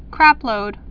(krăplōd)